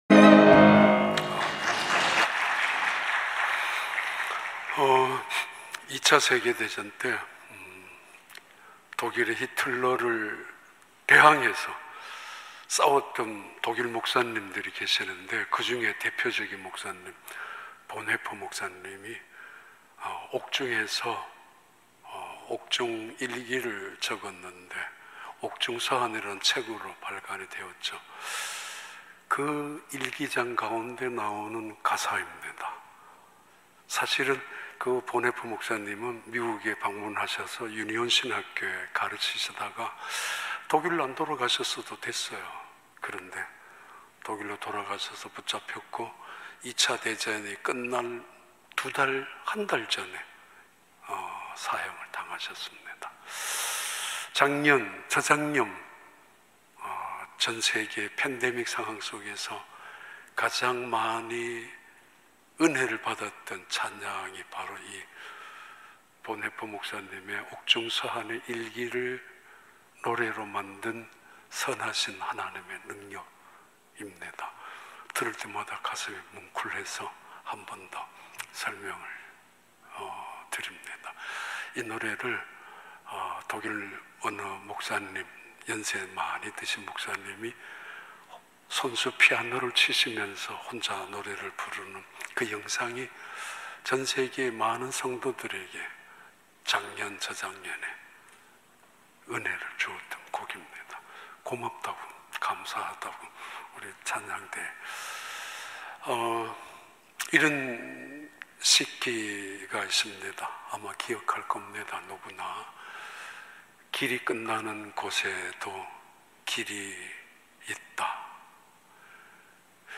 2022년 2월 13일 주일 3부 예배